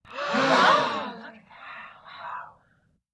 Звуки вау
Звук радостного удивления